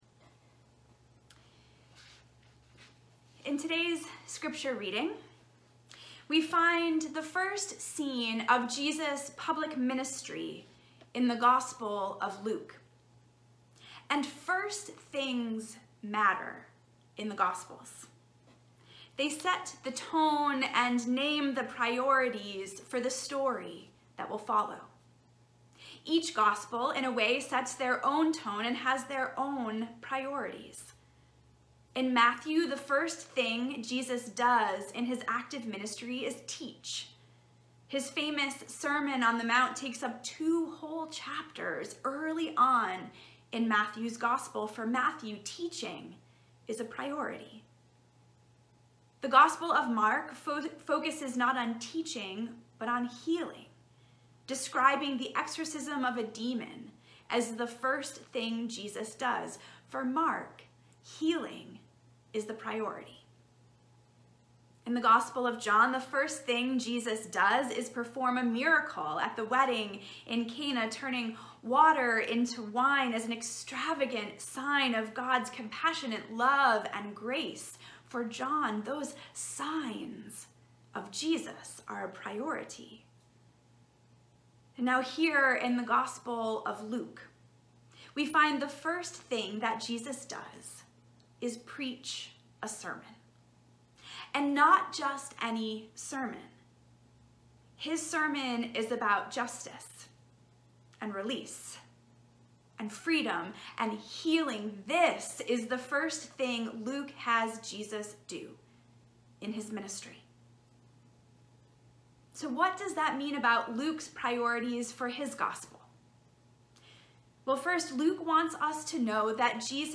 Sermons - Old South Union Church – Weymouth